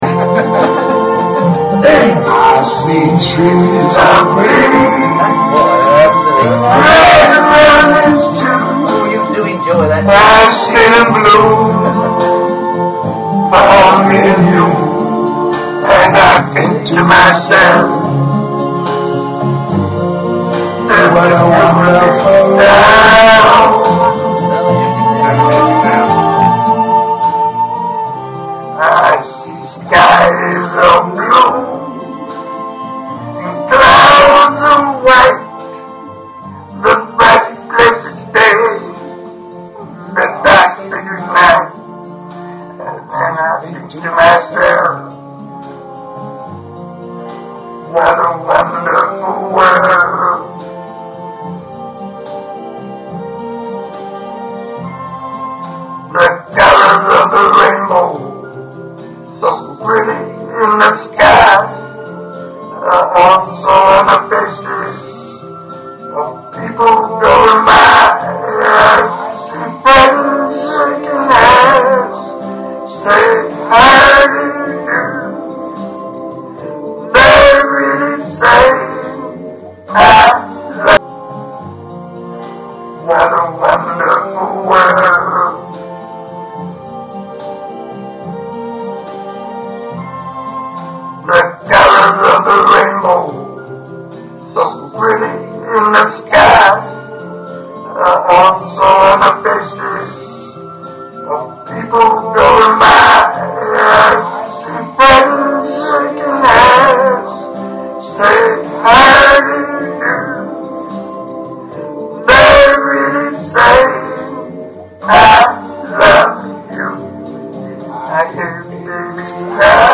* Louis Armstrong sings along with recording of "It's a Wonderful World"